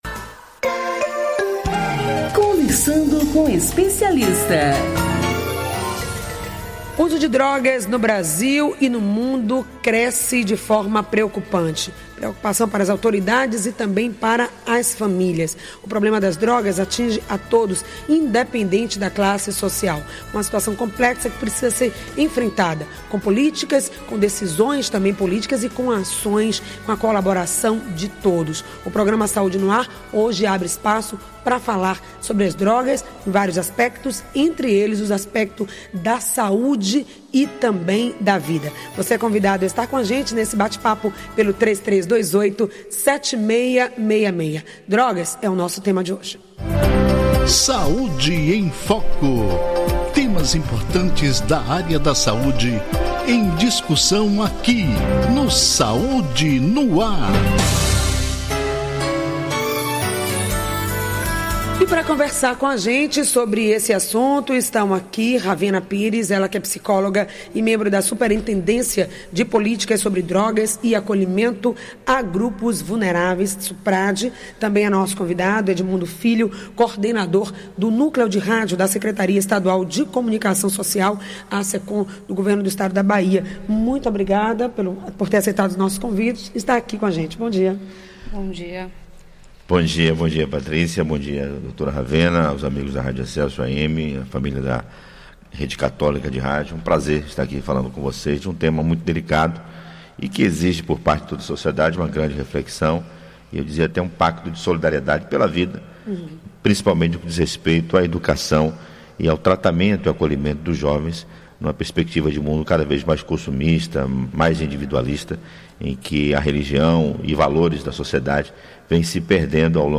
Eles conversaram sobre o uso de drogas. Um grave problema social e de saúde que afeta diversos países, o governo e as famílias. Ouça a entrevista: